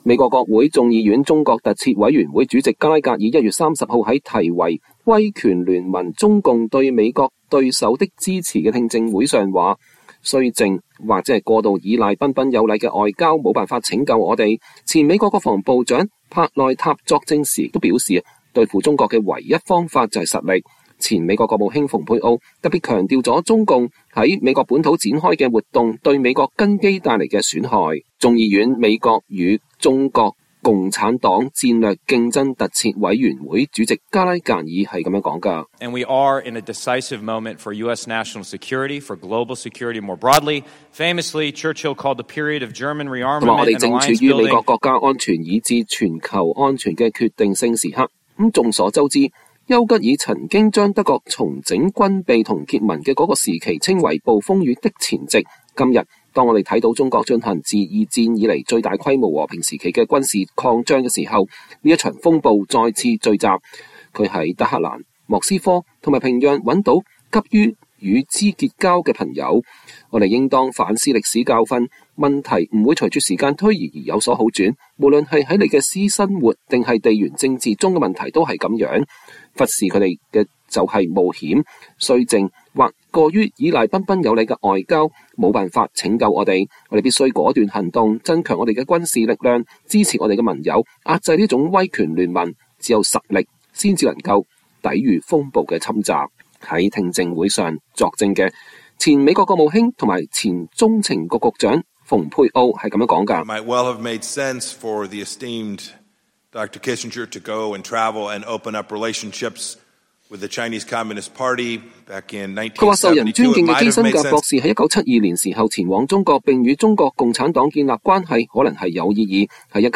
美國國會眾議院中國特設委員會1月30日召開聽證會